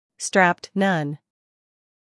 音标
英音/ stræpt / 美音/ stræpt /